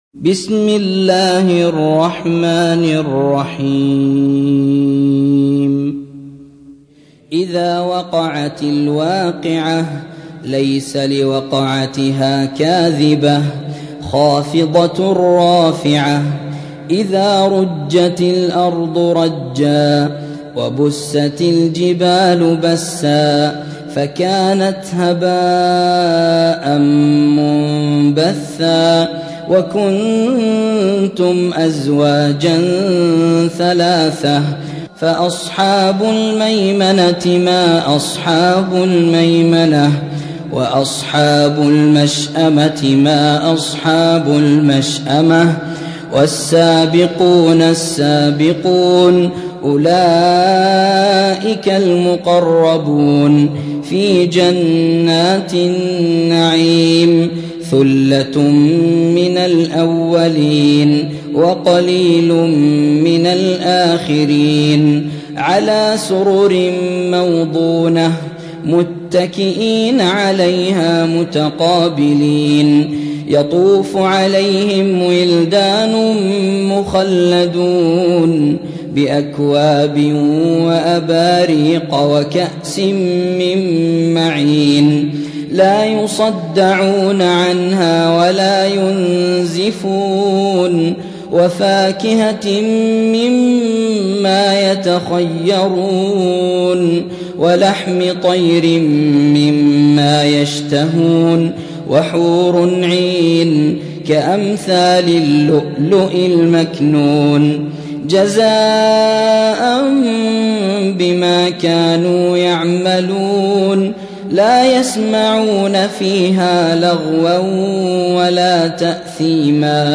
56. سورة الواقعة / القارئ